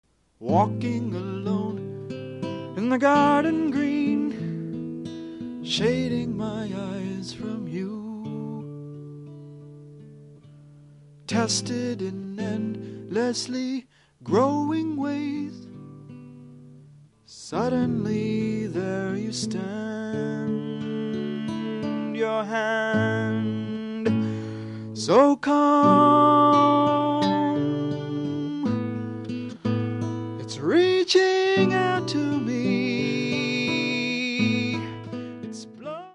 Songs on Guitar & Piano 8-12-78 (Double LP length)
2-track cassette original master